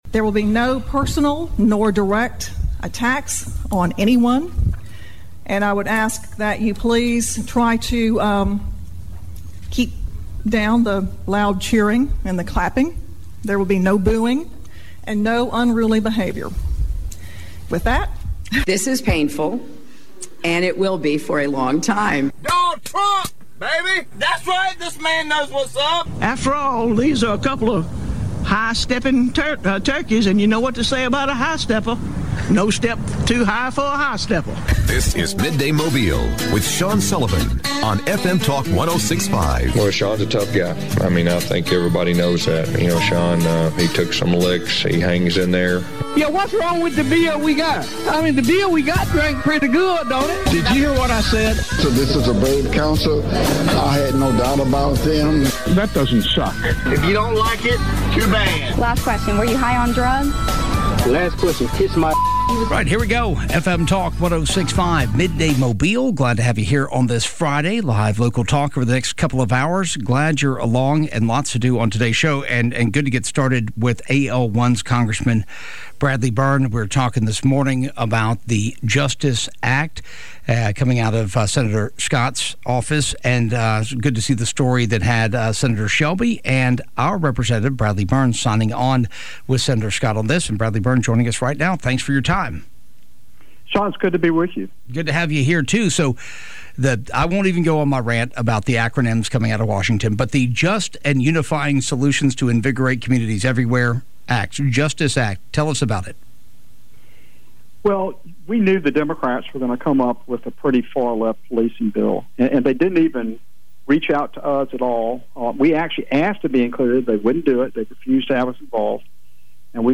Congressman Bradley Byrne joins the show to discuss his endorsement of Jerry Carl in the AL1 Congressional race and race relations in the US.